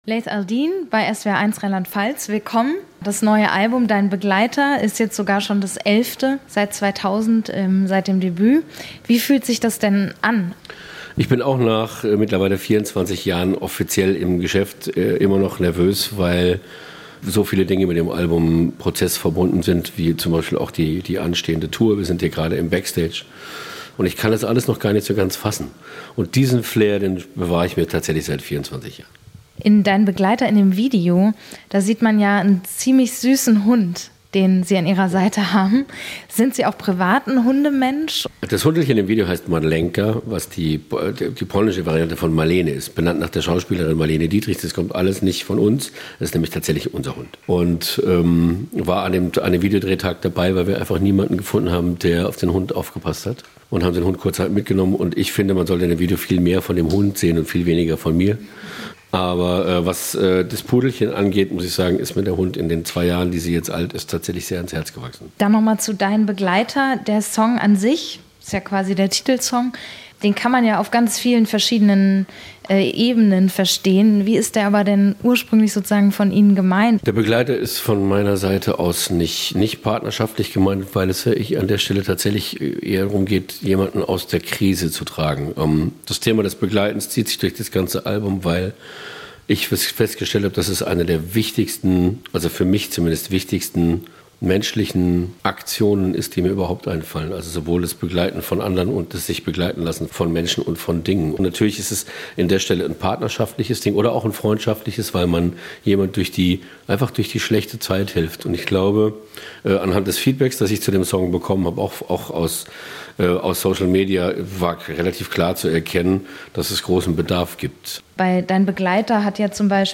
SWR1 Interview